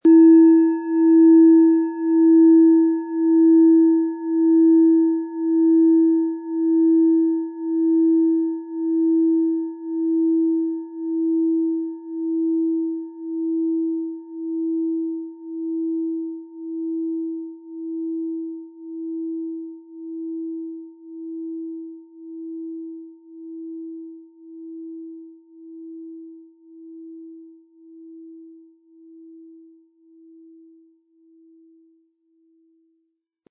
• Mittlerer Ton: Mond
Wie klingt diese Planetenton-Klangschale Hopi-Herzton?
Um den Originalton der Schale anzuhören, gehen Sie bitte zu unserer Klangaufnahme unter dem Produktbild.
Durch die überlieferte Fertigung hat diese Schale vielmehr diesen außergewöhnlichen Ton und die intensive Berührung der mit Liebe hergestellten Handarbeit.
PlanetentöneHopi Herzton & Mond
HerstellungIn Handarbeit getrieben
MaterialBronze